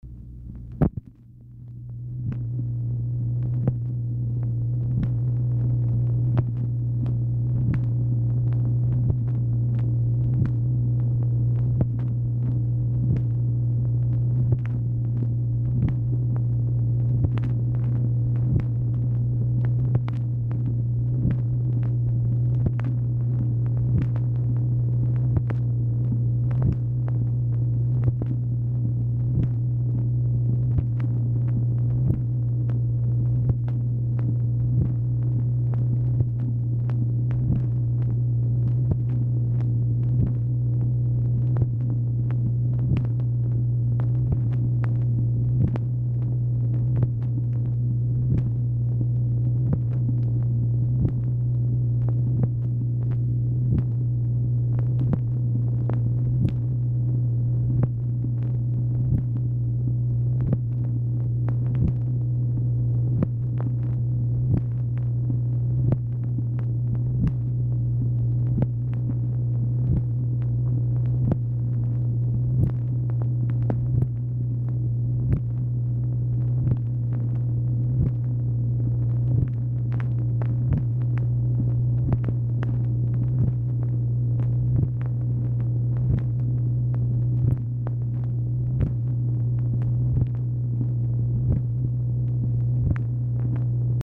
MACHINE NOISE
Format Dictation belt
Specific Item Type Telephone conversation